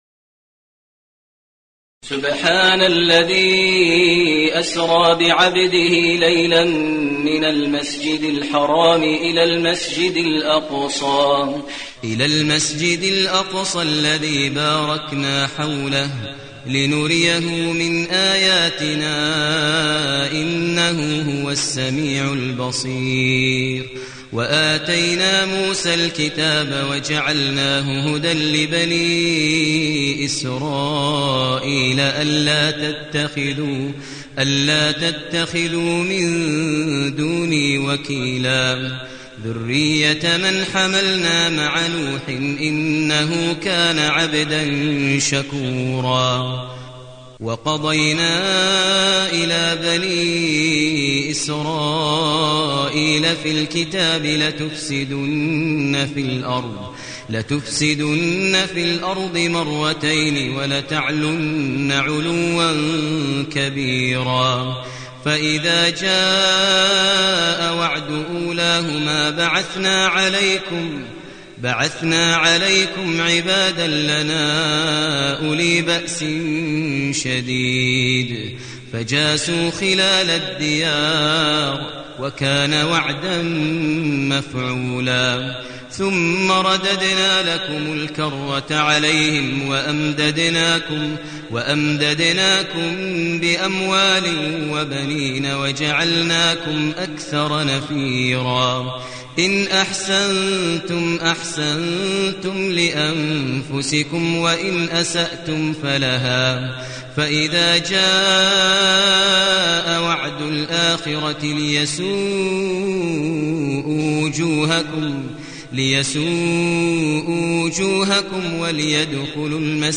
المكان: المسجد الحرام الشيخ: فضيلة الشيخ ماهر المعيقلي فضيلة الشيخ ماهر المعيقلي الإسراء